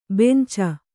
♪ benca